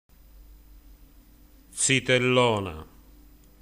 Alcuni vocaboli del dialetto Ruffanese.